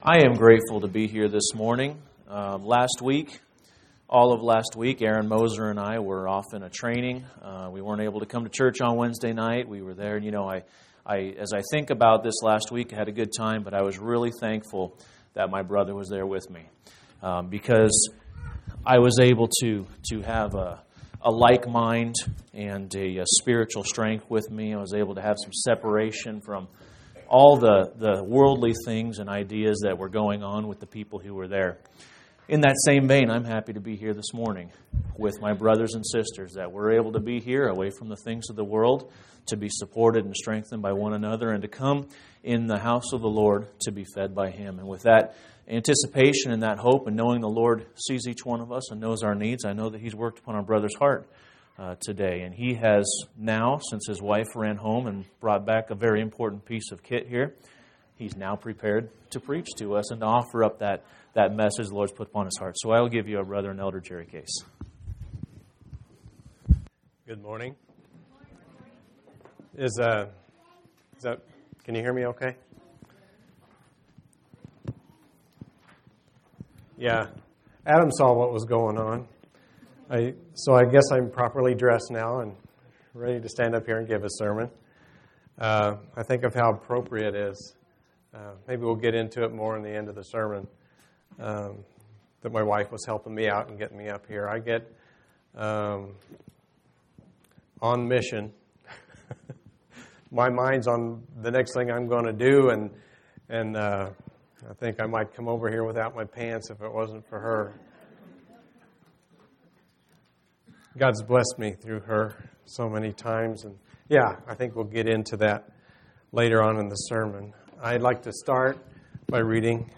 12/13/2015 Location: Phoenix Local Event